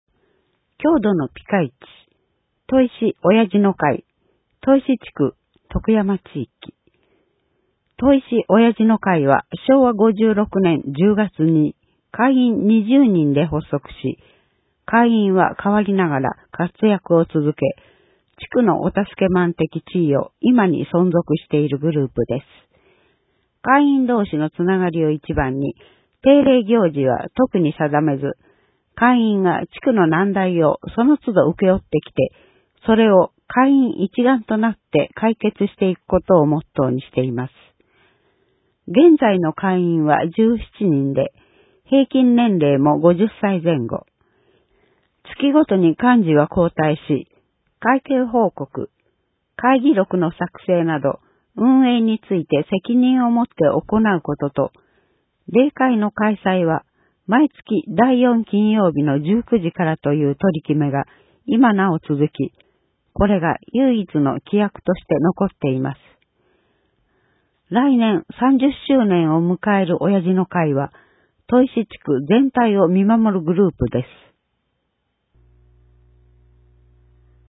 音訳広報
広報しゅうなんを、音読で収録し、mp3形式に変換して配信します。
この試みは、「音訳ボランティアグループともしび」が、視覚障害がある人のために録音している音読テープを、「点訳やまびこの会」の協力によりデジタル化しています。